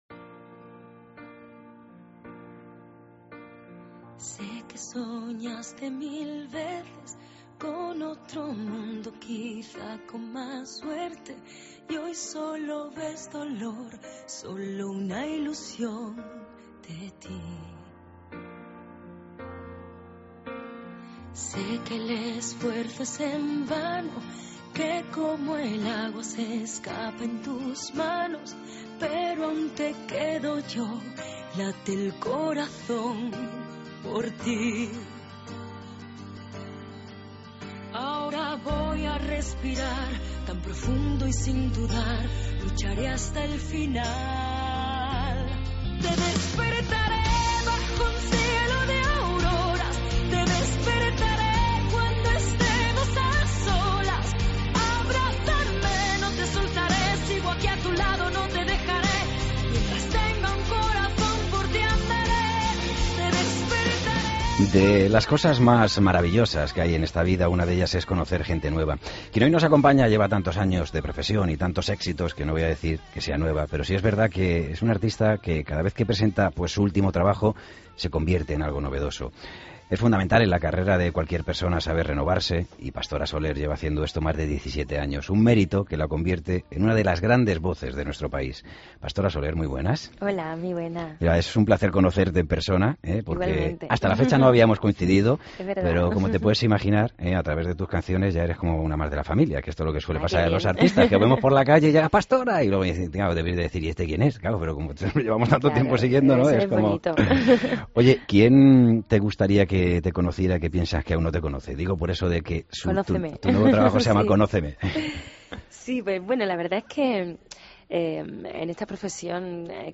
Entrevista a Pastora Soler